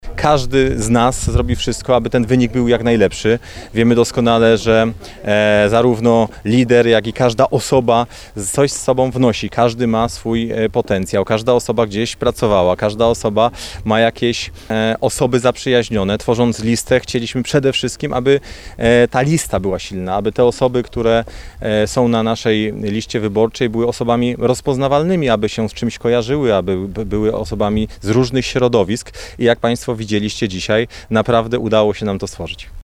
Z drugiej strony mamy wiele osób nowych, nowych twarzy, młodych, uśmiechniętych, którzy chcą coś zrobić dla naszego regionu – komentował z kolei minister Grzegorz Puda ("dwójka" na liście).